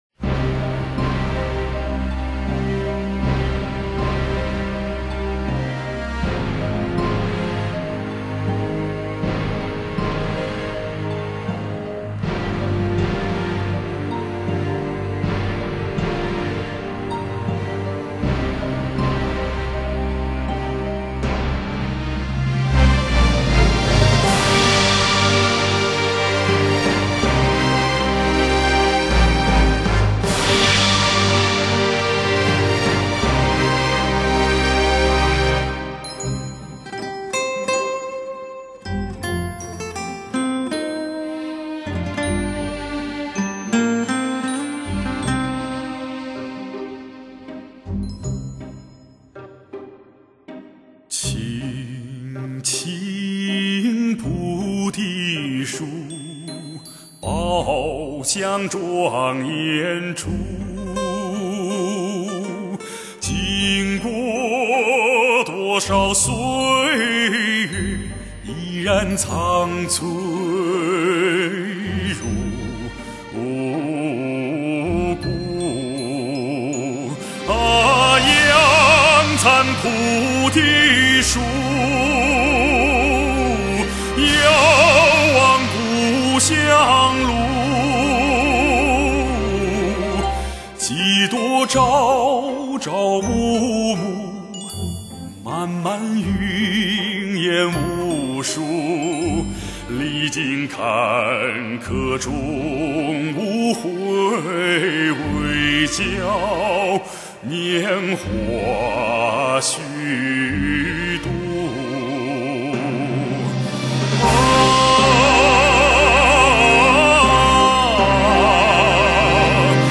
人声瑰丽 动态强横 绝佳发烧